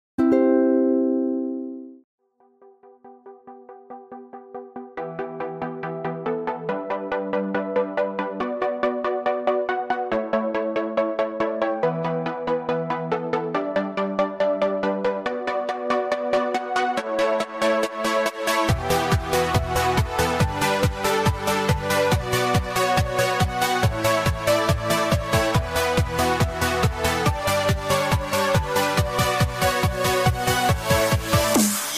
Categoria Telefone